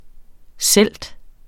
Udtale [ ˈsεlˀd ]